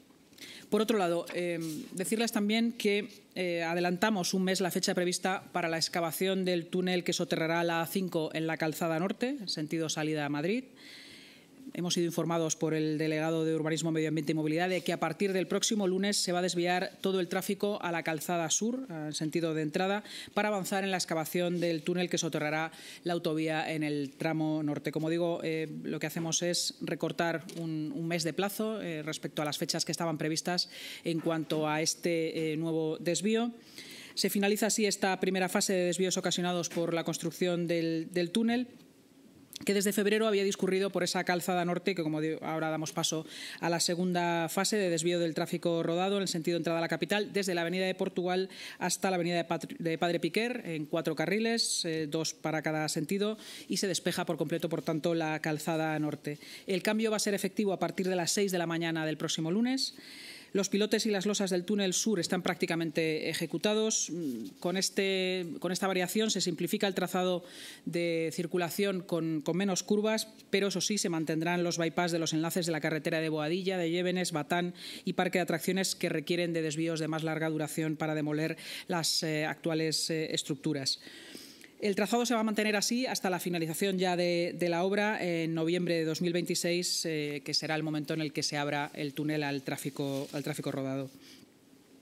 La vicealcaldesa y portavoz municipal, Inma Sanz, ha explicado en la rueda de prensa posterior a la Junta que finaliza así la fase 1 de los desvíos ocasionados por la construcción del subterráneo de la A-5 en la calzada sur (en sentido entrada a Madrid) que, desde febrero, había discurrido por la calzada norte (en sentido salida de Madrid).